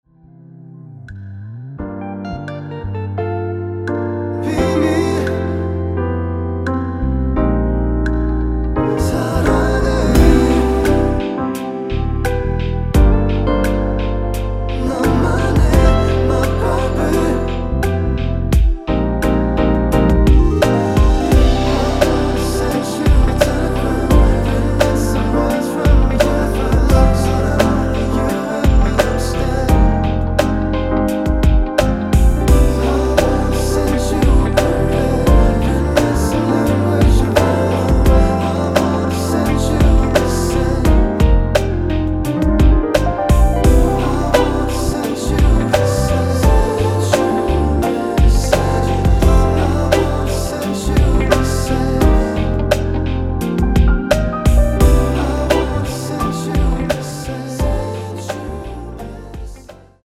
원키 코러스 포함된 MR입니다.(미리듣기 확인)
Eb
앞부분30초, 뒷부분30초씩 편집해서 올려 드리고 있습니다.